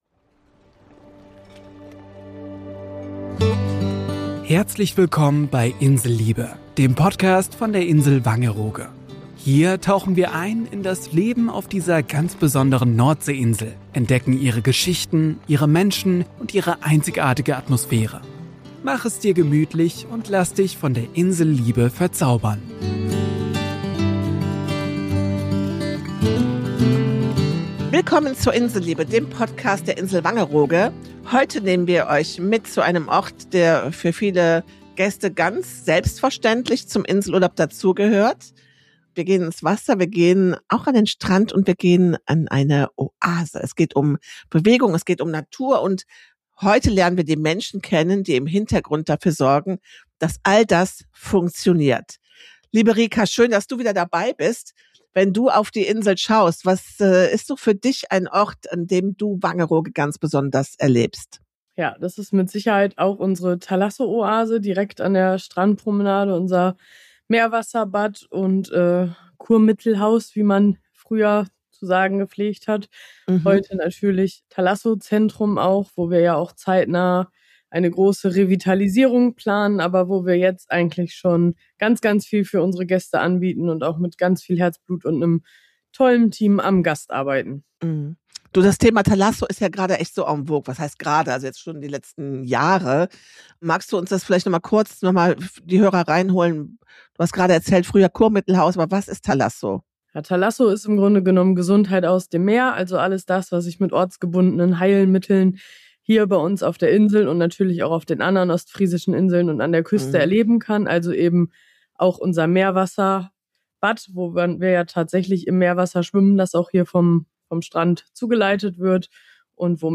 zu Gast im Studio